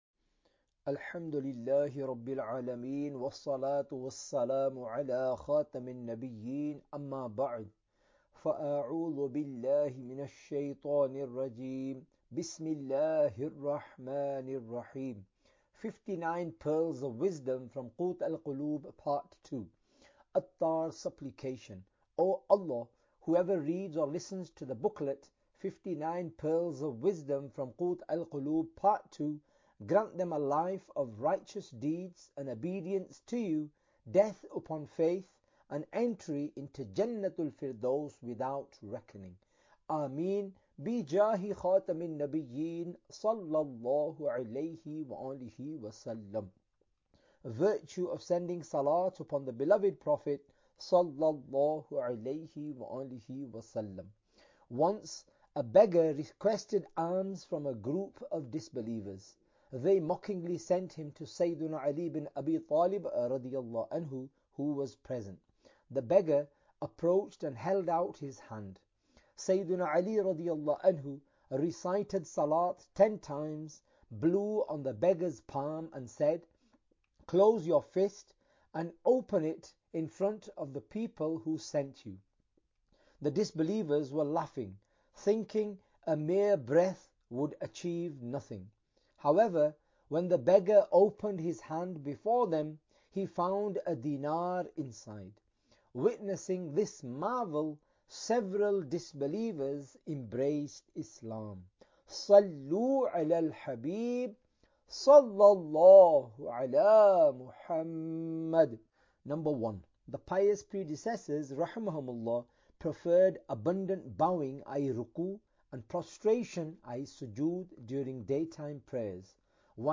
Audiobook - 59 Pearls of Wisdom From Qut Al Qulub - Part 02 (English)